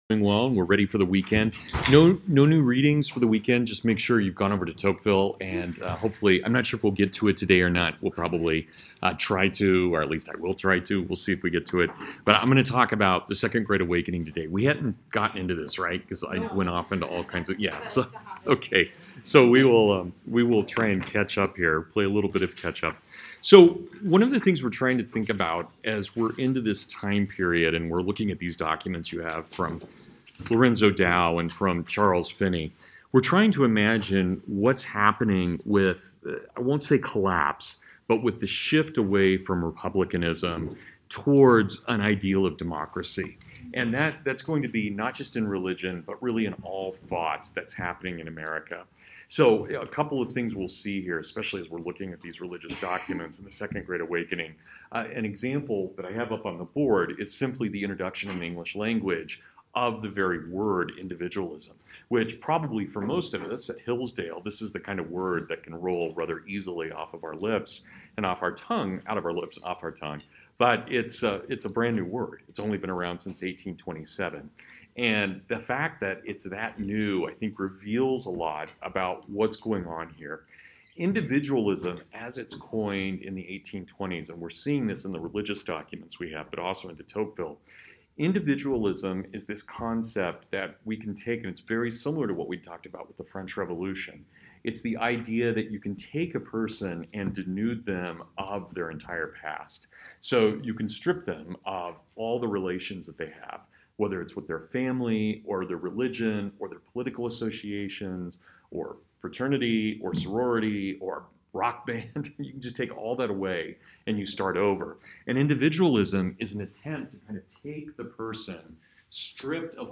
The Second Great Awakening (Full Lecture)
Yesterday, my flu was still lingering, but it wasn’t incapacitating. Still, if my lecture seems a bit off, blame in on the flu!!!
american-heritage-second-great-awakening-2017.mp3